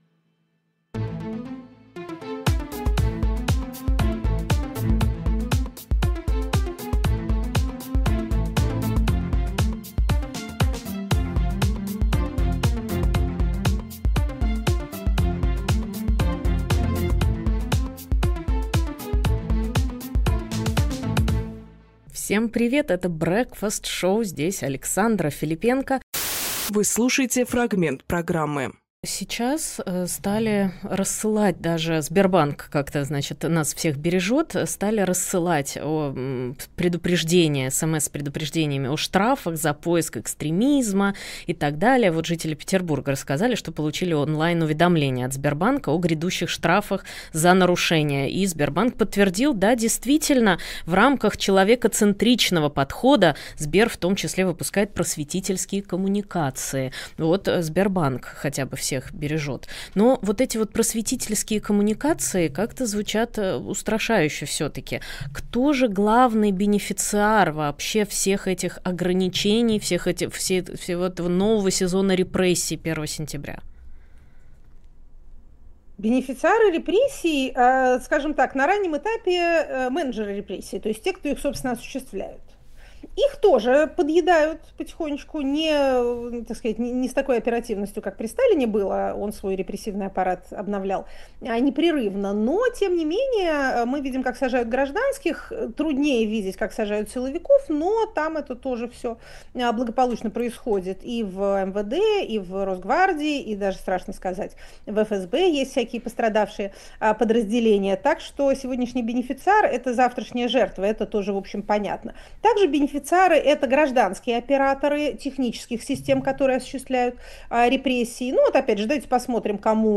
Фрагмент эфира от 01.09.25